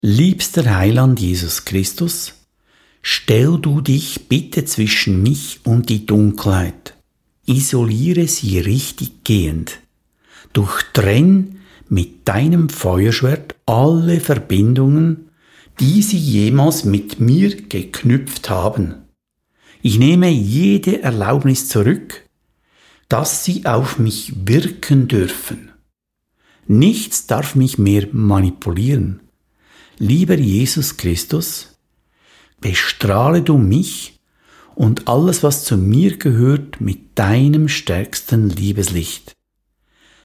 diese nun auch als Hörbücher anzubieten.